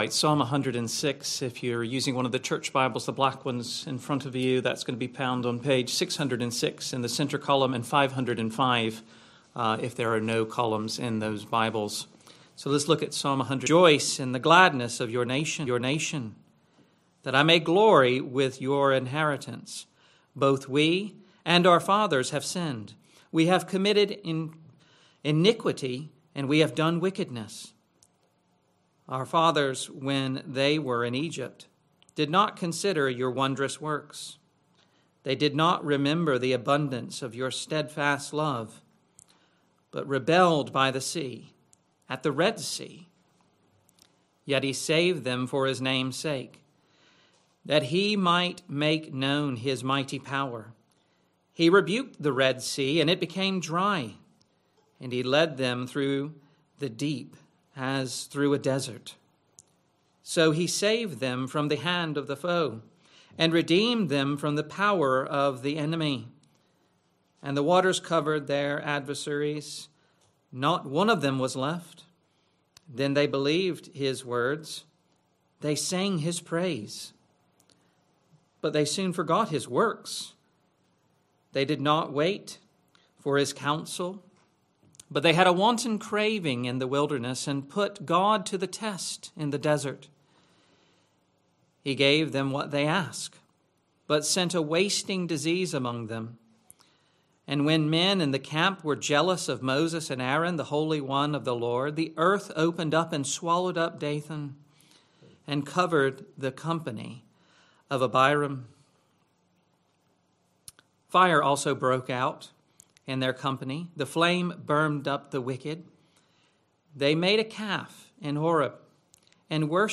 Christ Church Sermon Archive
Sunday PM Service Sunday 1st February 2026 Speaker